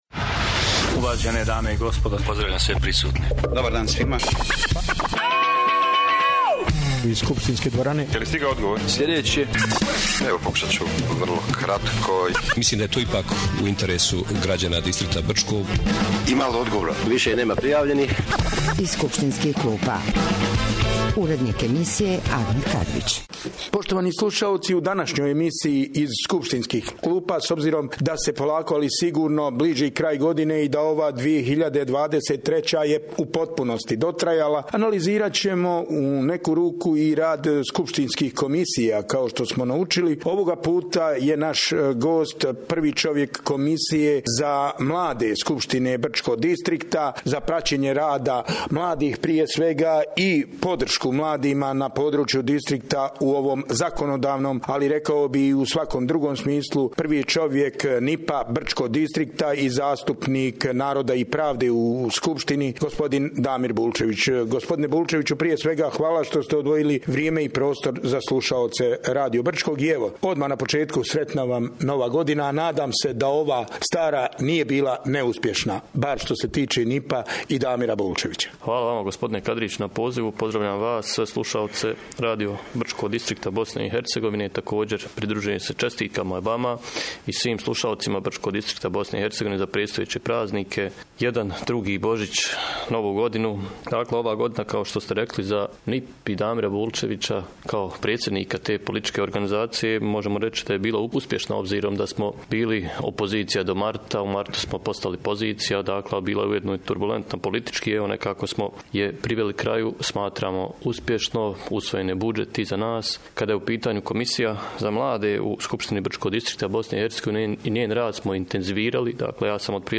Sa Damirom Bulčevićem smo razgovarali o aktuelnom trenutku mladih u Brčko distriktu BiH.